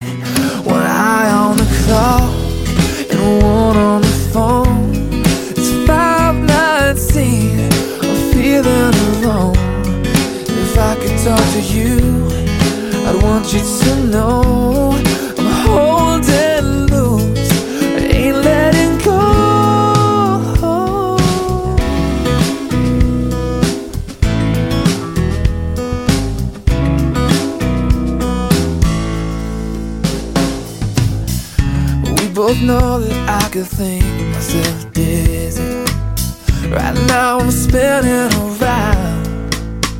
легкий рок
акустика , гитара